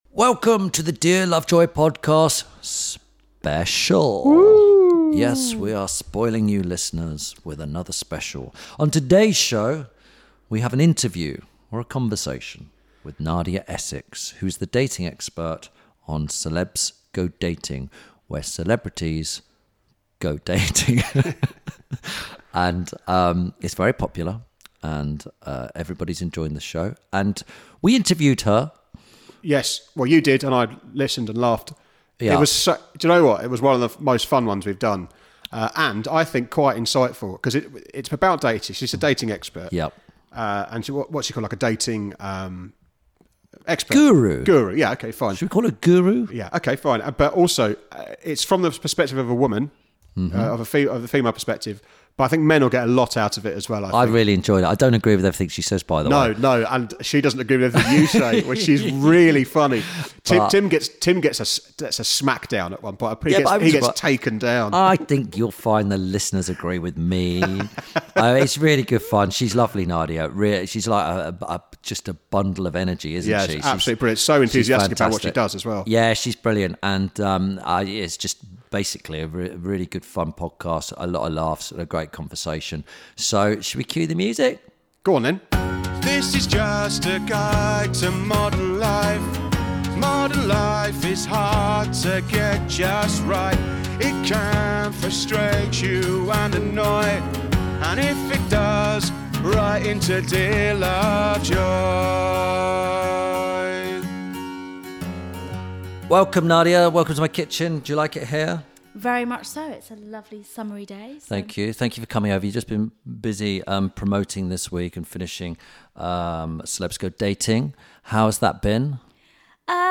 This week Tim Lovejoy talks to ‘Celebs Go Dating’ host and dating expert Nadia Essex about all aspects of courtship.